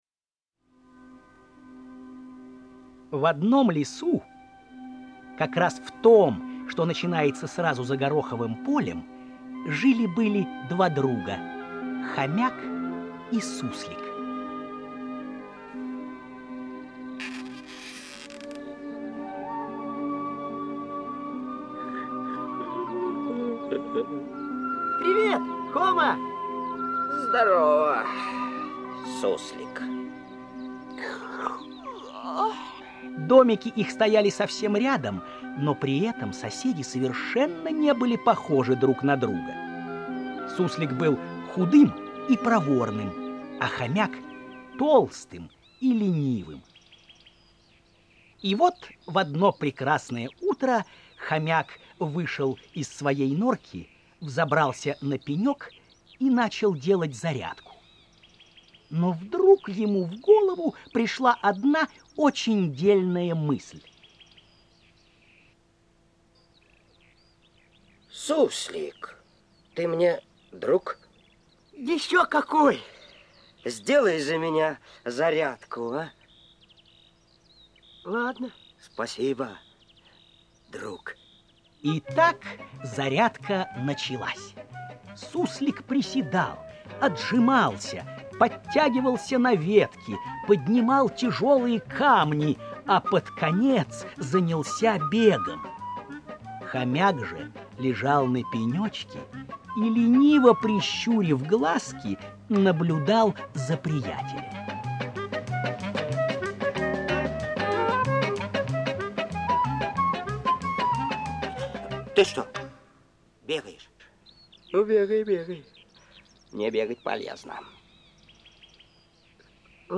Аудио-сказка "Приключения Хомы и Суслика"